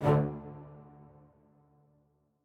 strings14_12.ogg